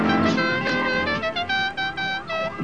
Another Dixie Horn
horn5.wav